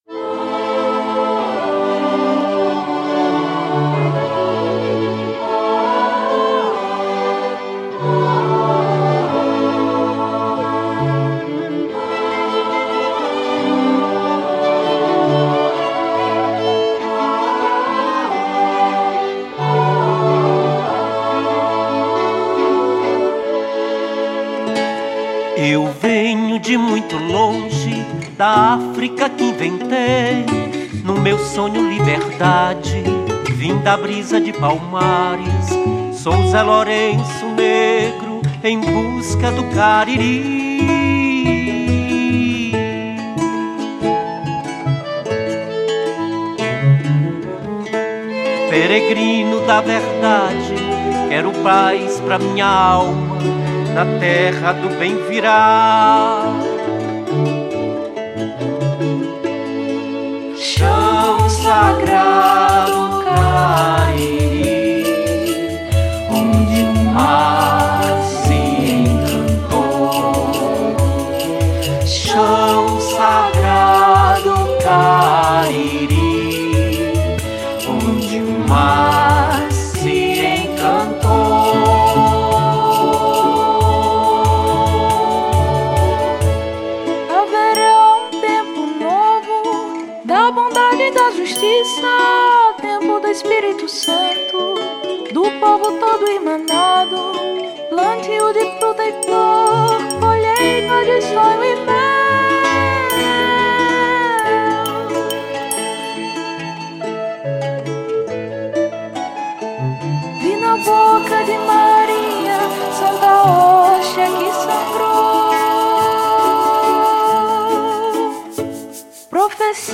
Gênero: Regional